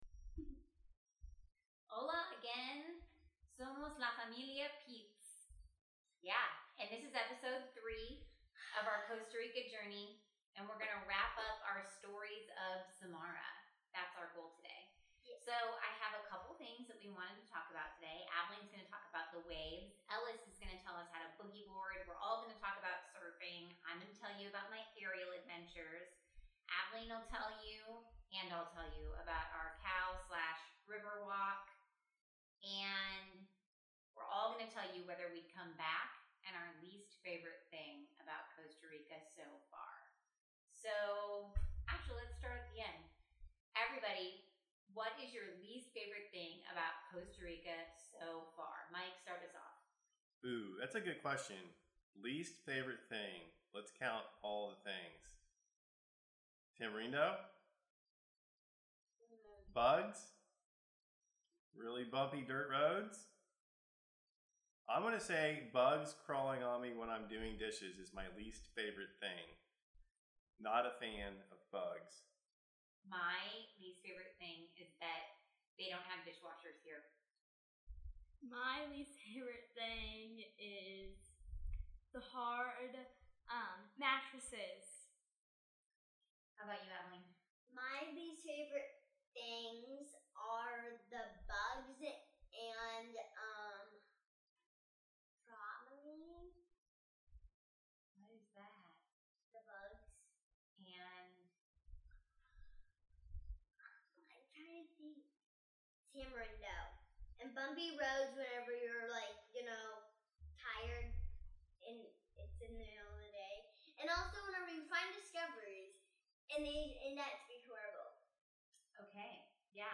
We're wrapping up our time in Samara and headed to Monte Verde tomorrow! Sorry in advance for the tapping mic noise...
I tried to take it out but the noise seems to be stuck.